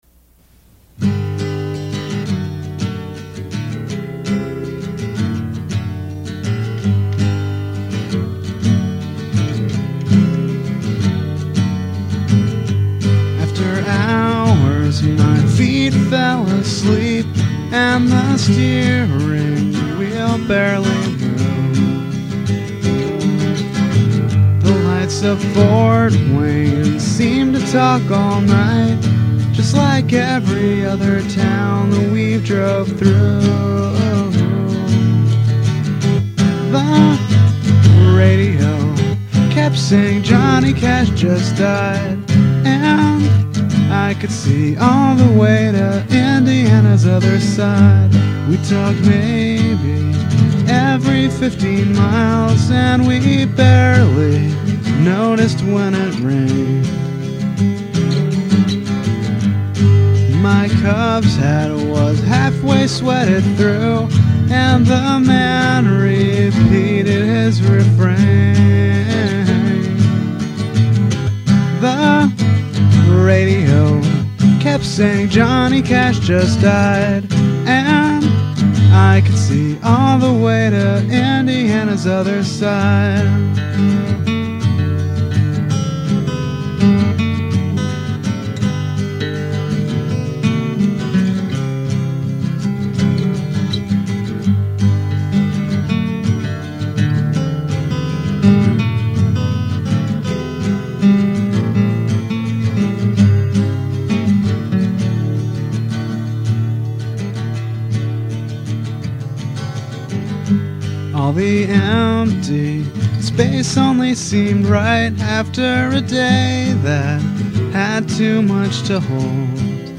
Your Minneapolis-based musician and songwriter